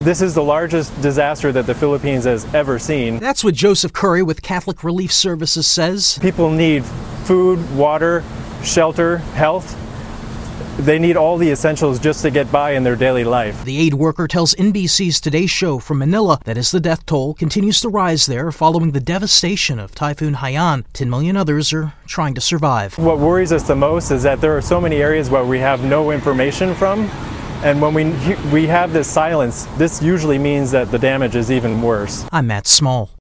Recovery and relief efforts are underway days after a massive typhoon tore through the Philippines. AP correspondent